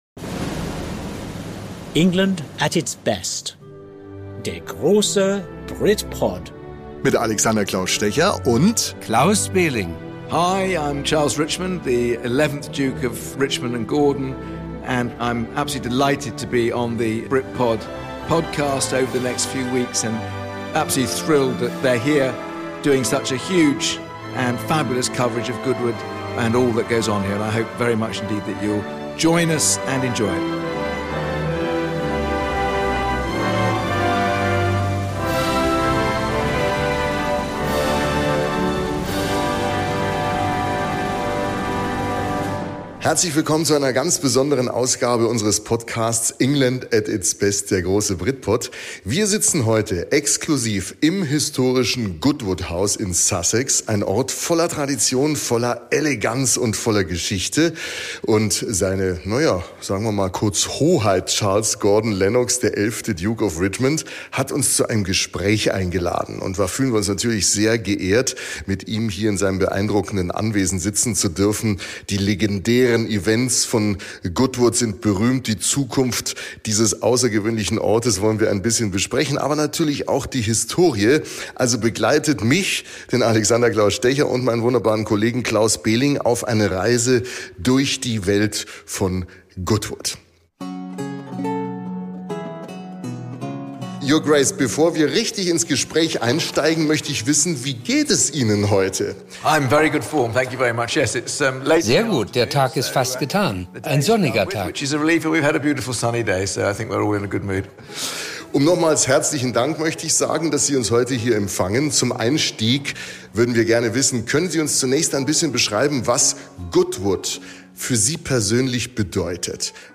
Exklusives Interview: Charles Gordon-Lennox, 11. Duke of Richmond über Tradition & Innovation (Deutsche Version, Teil 1) ~ BRITPOD - England at its Best Podcast